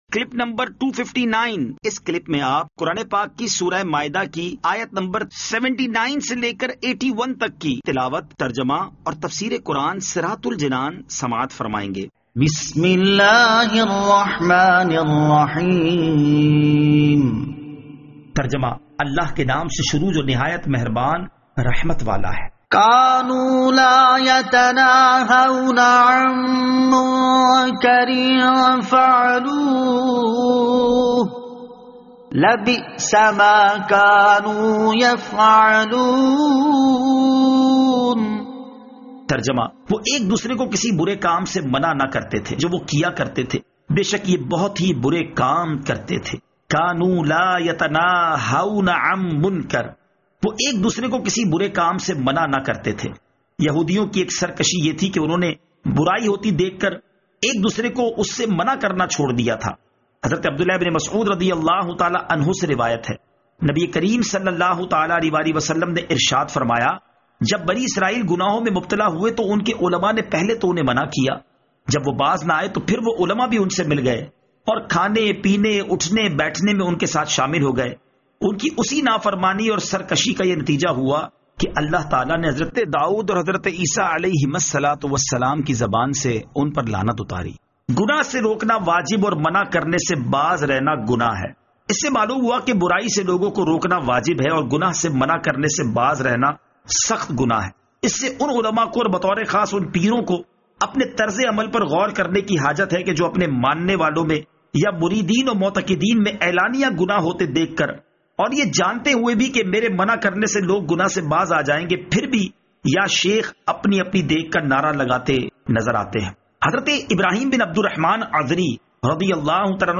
Surah Al-Maidah Ayat 79 To 81 Tilawat , Tarjama , Tafseer